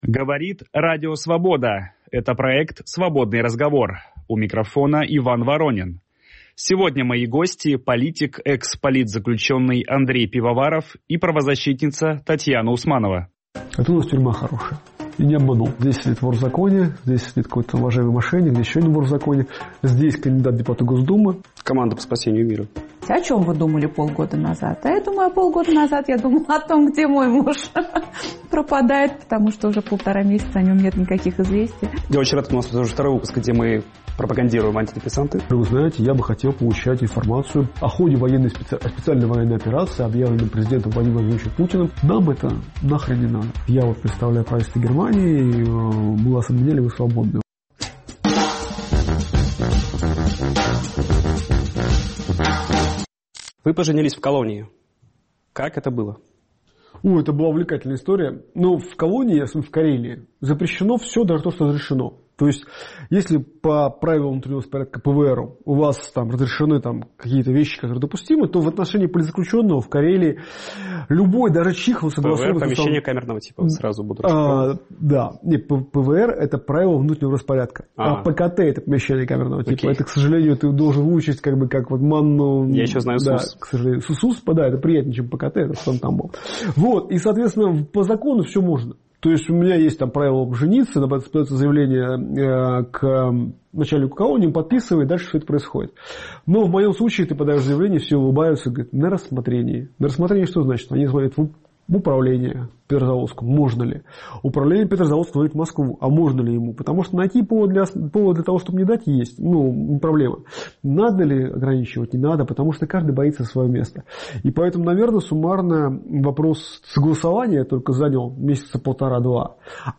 В интервью Радио Свобода